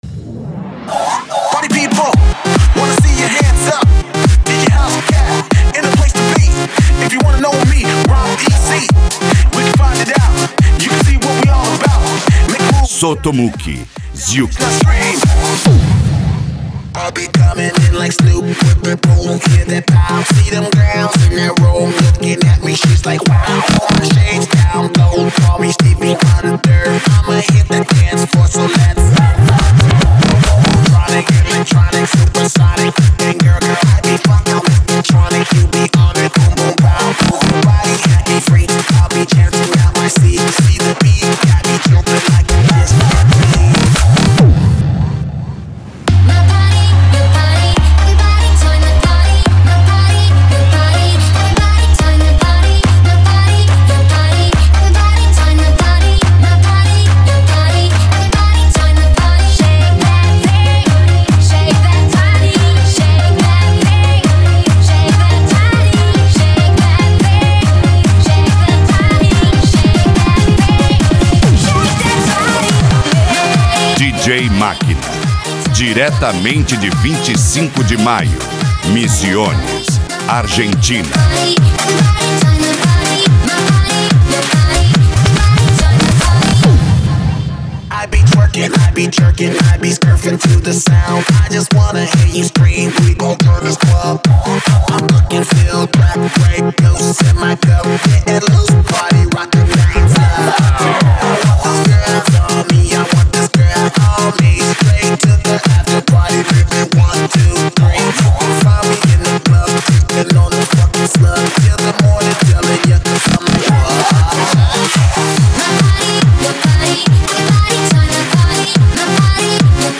Eletronica
japan music
PANCADÃO